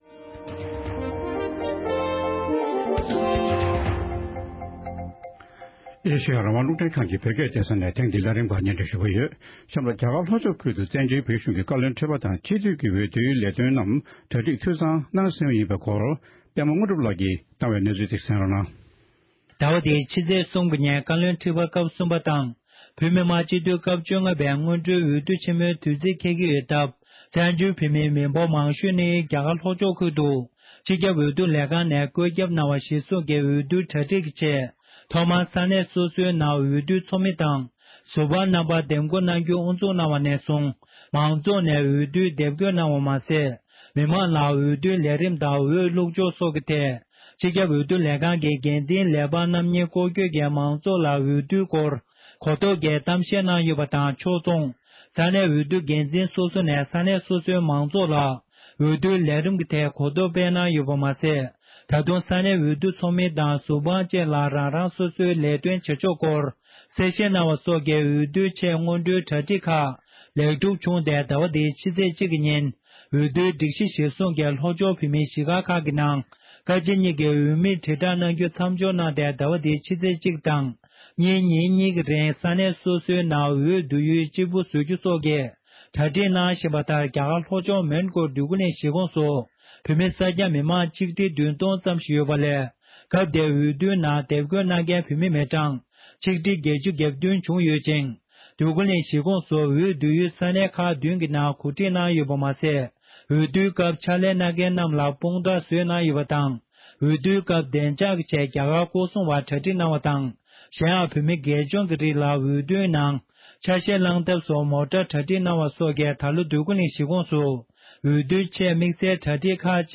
རྒྱ་གར་ལྷོ་ཕྱོགས་ནས་བཏང་འབྱོར་བྱུང་བའི་གནས་ཚུལ་ཞིག་ལ་གསན་རོགས༎